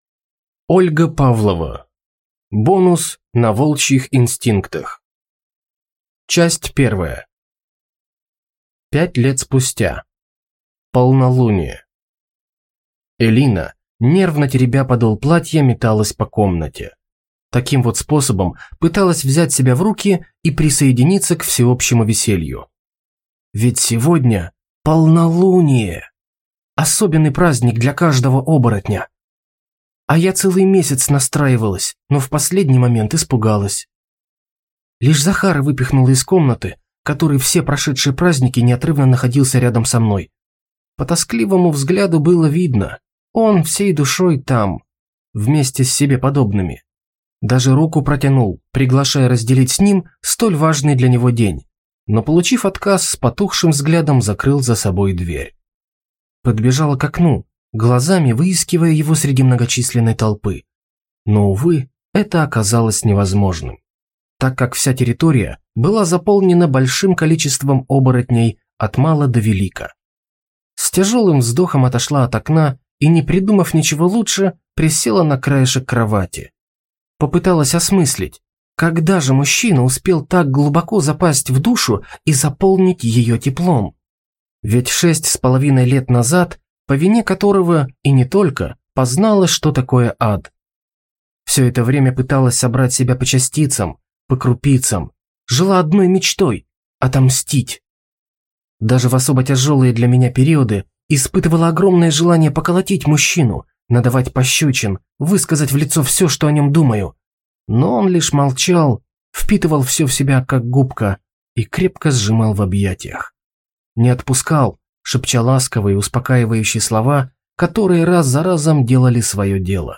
Аудиокнига Бонус На волчьих инстинктах | Библиотека аудиокниг